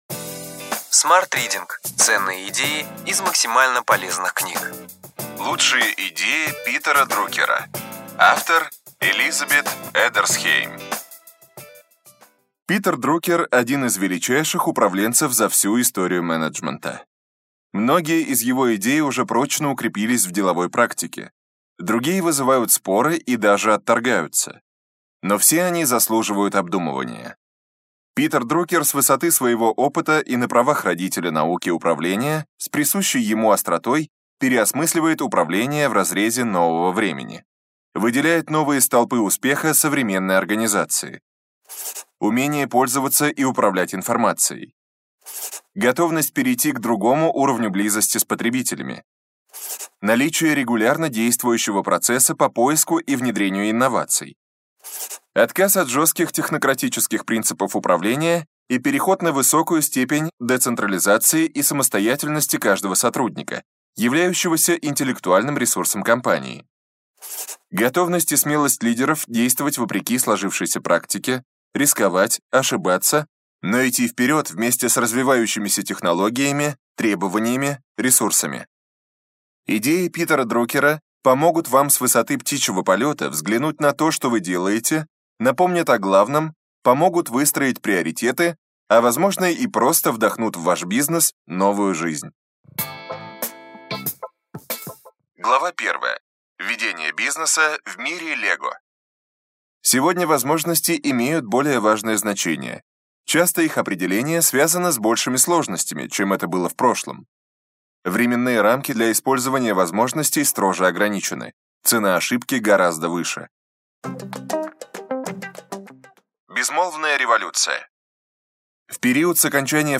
Аудиокнига Ключевые идеи книги: Лучшие идеи Питера Друкера.